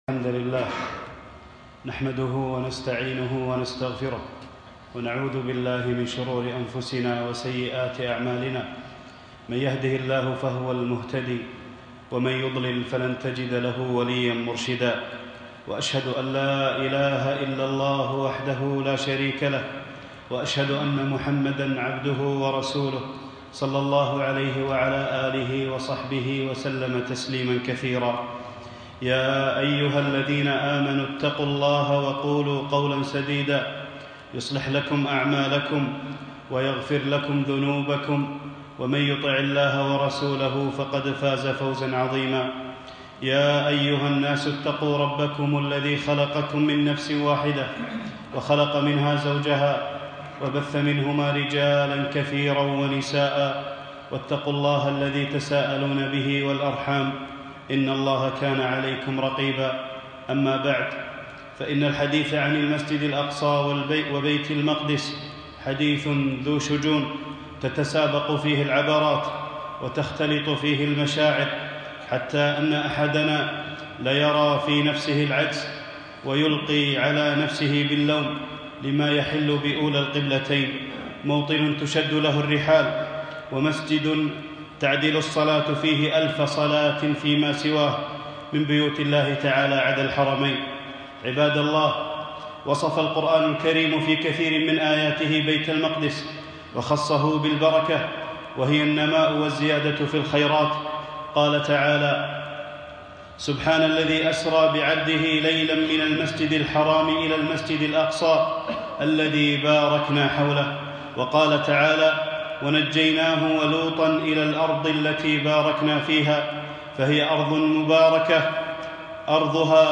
خطبة - دمعةٌ على الأقصى